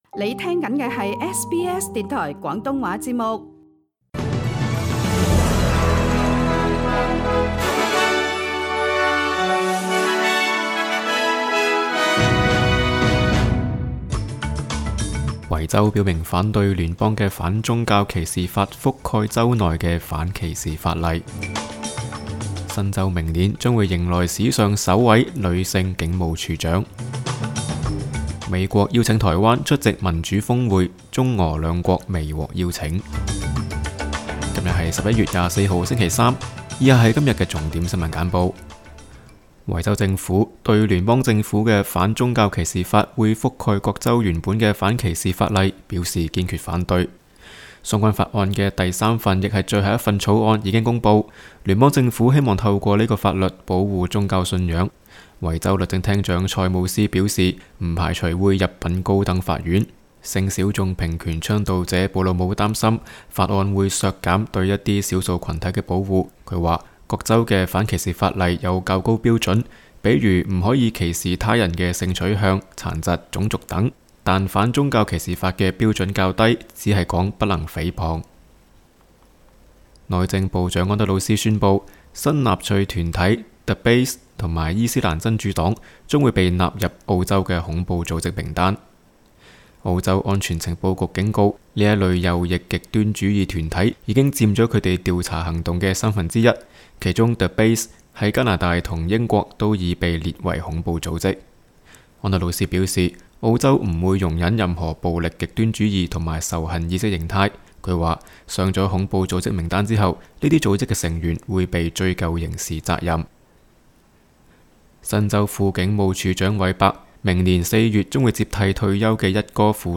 SBS 廣東話節目新聞簡報 Source: SBS Cantonese